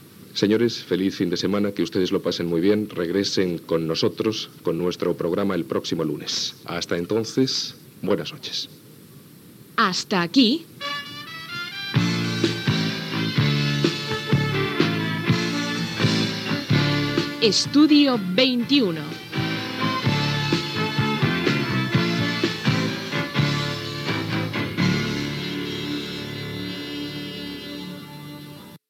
Identificació del programa i presentació d'un tema musical.
Comait del programa i careta de sortida.
Musical
FM